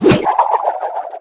grenade_launch_01.wav